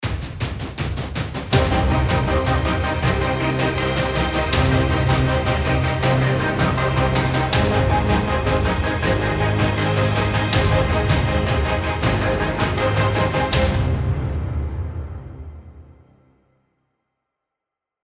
ActionDriving